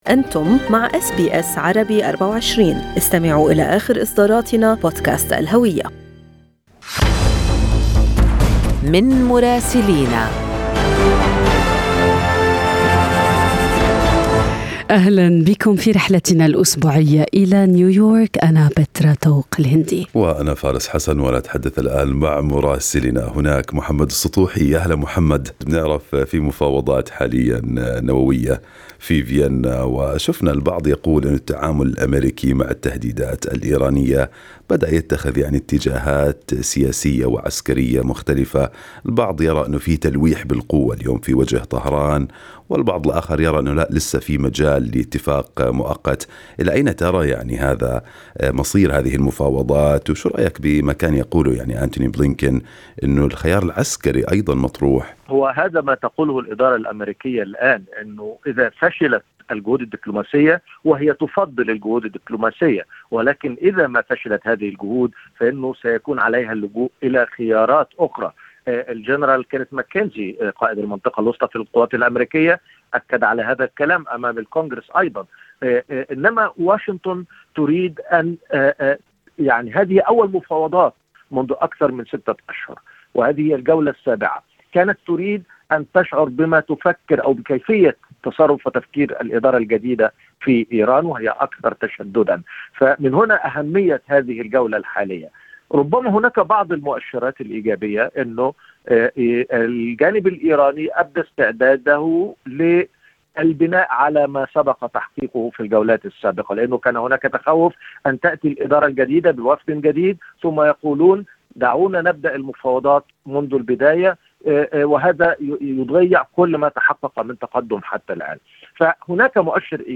من مراسلينا: أخبار الولايات المتحدة الأمريكية في أسبوع 2/12/2021